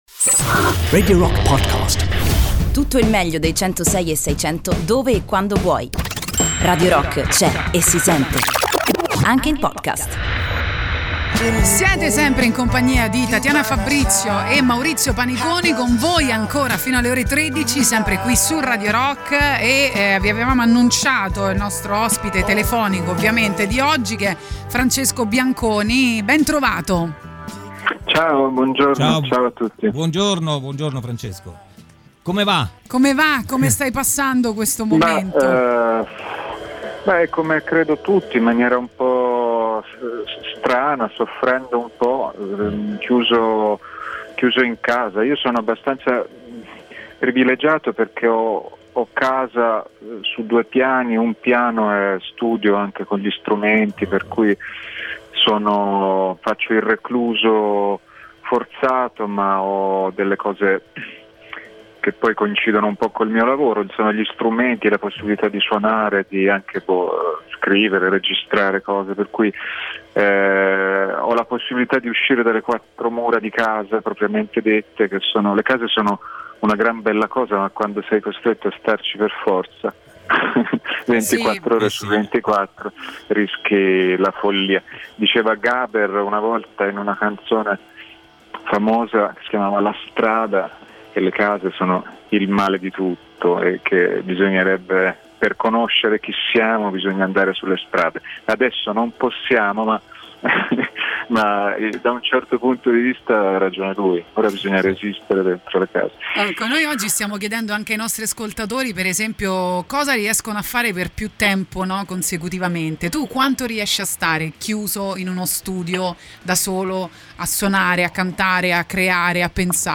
in collegamento telefonico